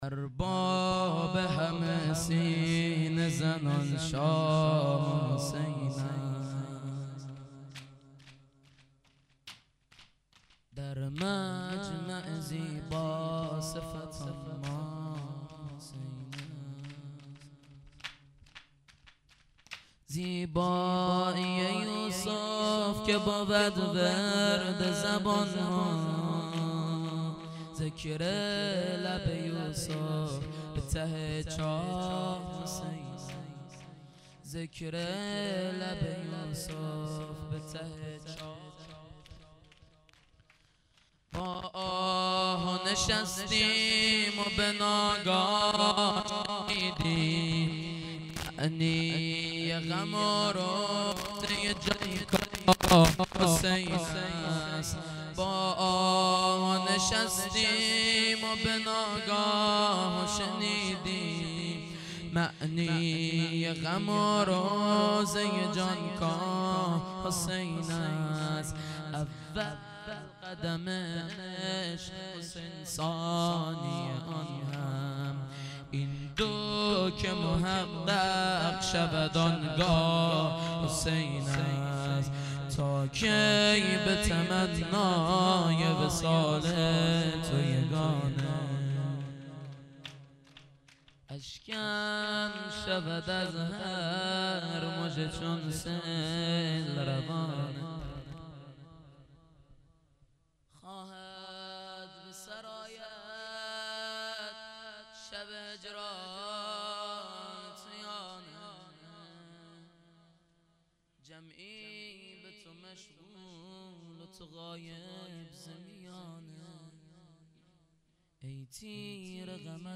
heiat-levaolabbasshab-shahadat-hazrat-roghaieh-4-zarb.mp3